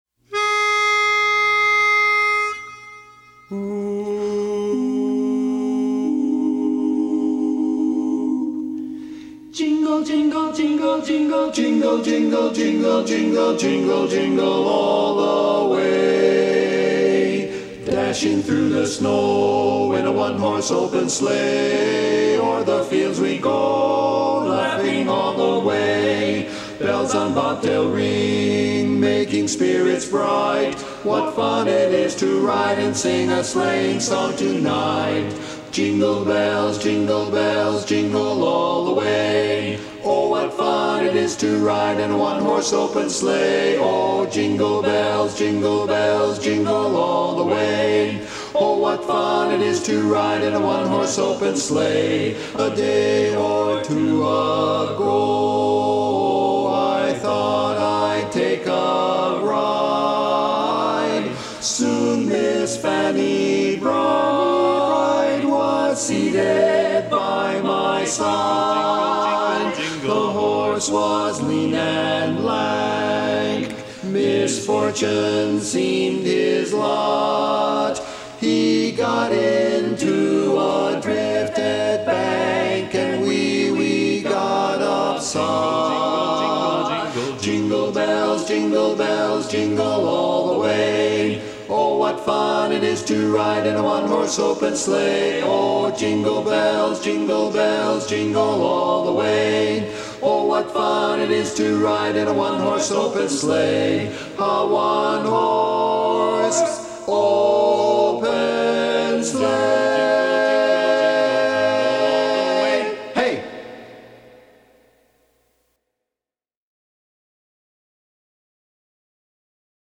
Barbershop
Bari